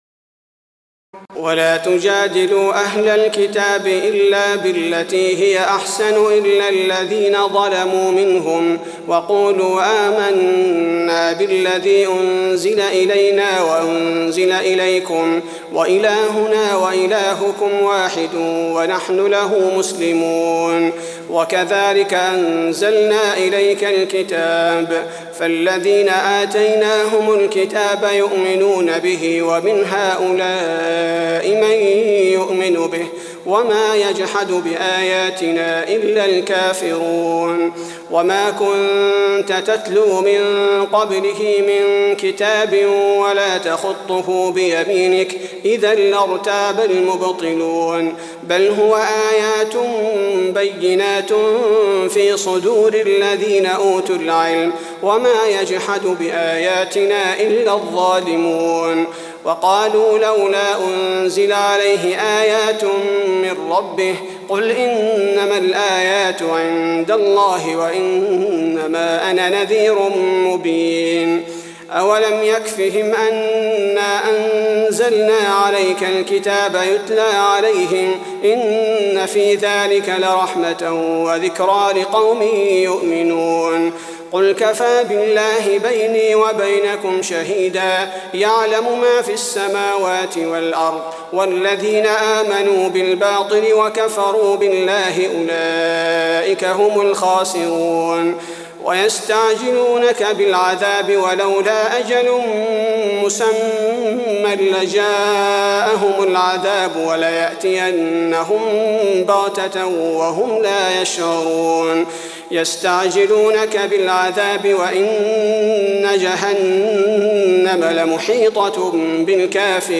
تراويح الليلة العشرون رمضان 1423هـ من سور العنكبوت (46-69) و الروم (1-53) Taraweeh 20 st night Ramadan 1423H from Surah Al-Ankaboot and Ar-Room > تراويح الحرم النبوي عام 1423 🕌 > التراويح - تلاوات الحرمين